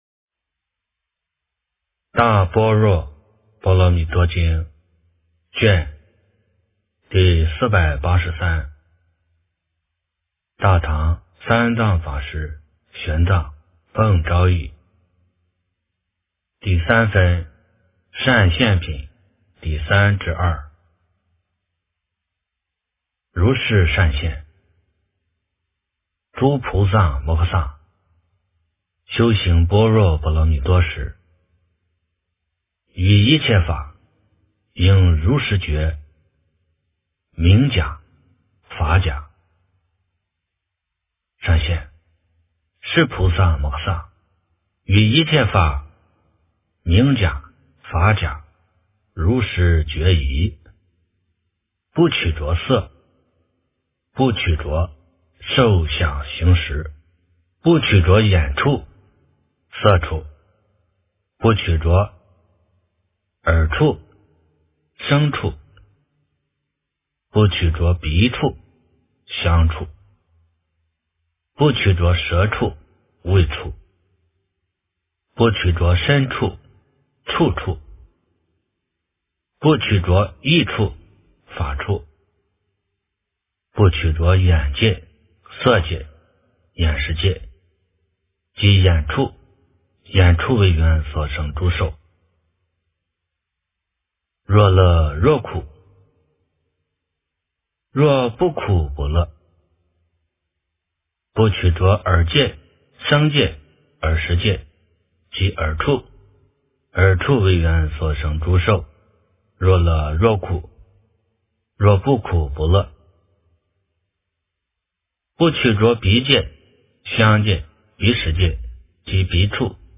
大般若波罗蜜多经第483卷 - 诵经 - 云佛论坛